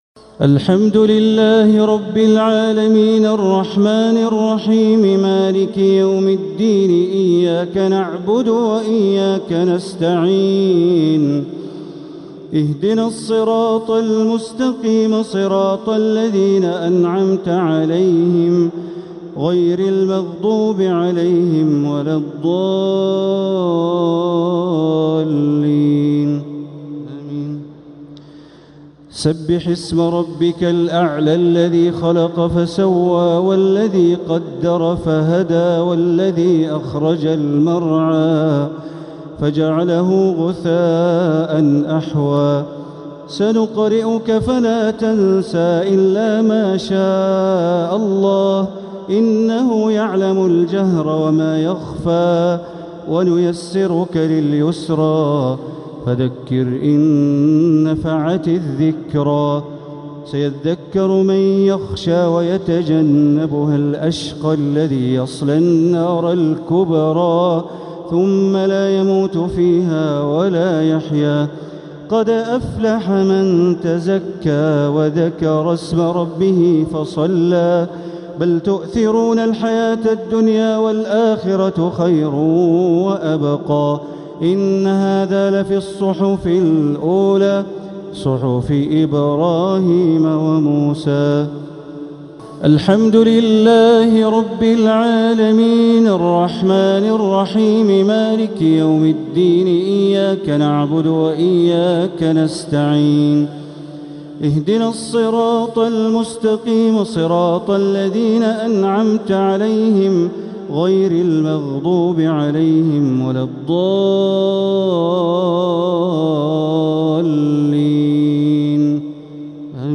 صلاة الشفع و الوتر ليلة 2 رمضان 1447هـ > تراويح 1447هـ > التراويح - تلاوات بندر بليلة